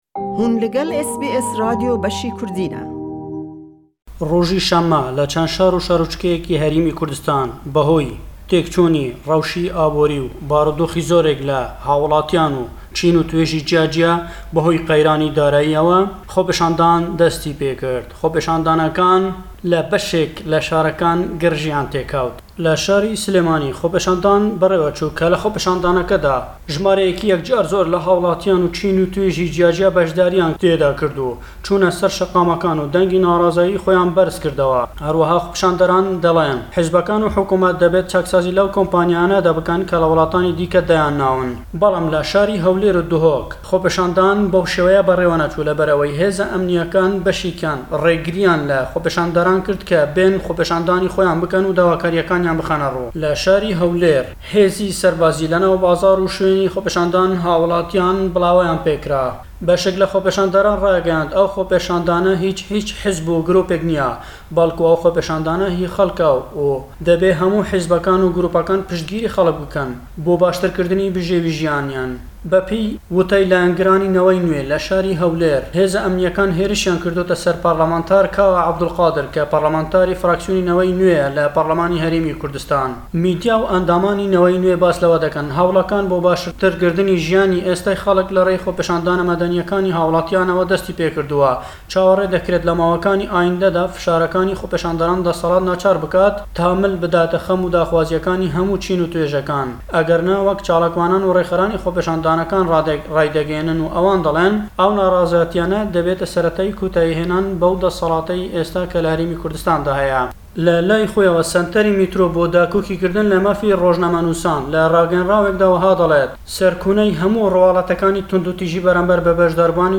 Lem raporte da le Herêmi Kurdistane we: xopîşandan le şarekanî herêmî Kurdistan berêwedeçêt, dessellatdaran rêgirî deken le xopîşandanekan le Hewlêr û Duhok, Jimarey tûşbûwanî COVID-19 le helkêşan daye le şarû şaroçkekanî herêmî Kurdistan.